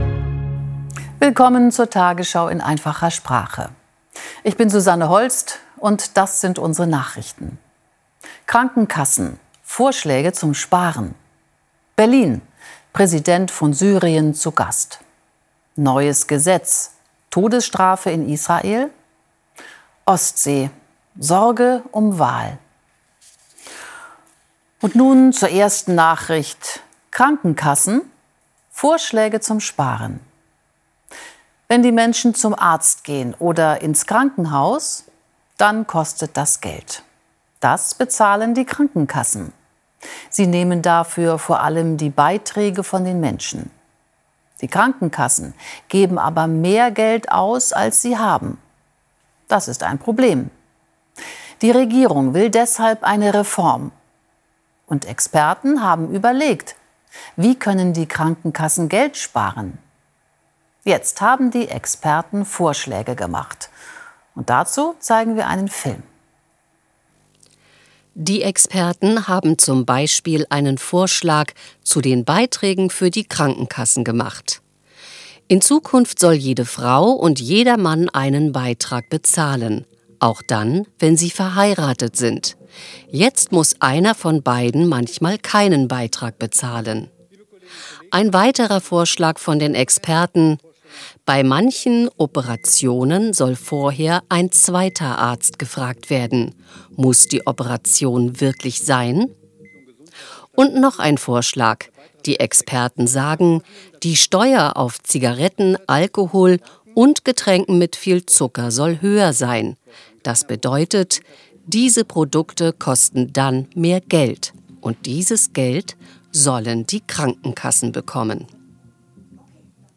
Bildung , Nachrichten